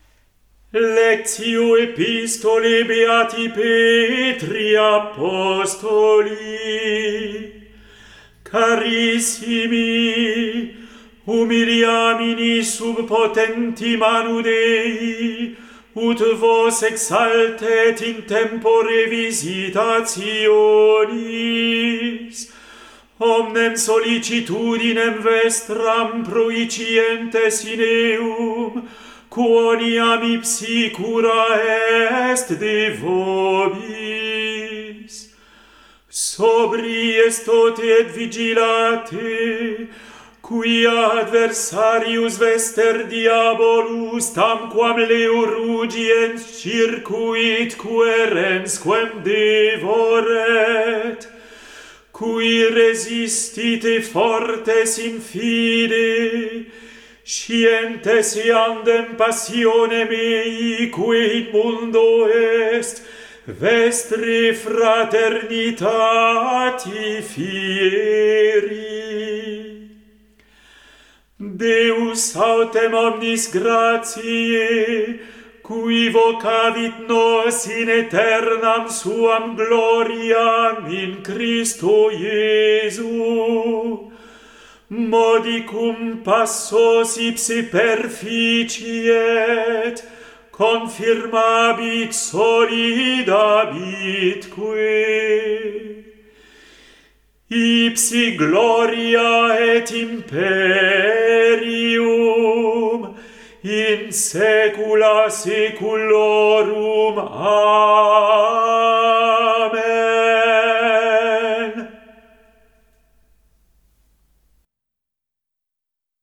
Epistola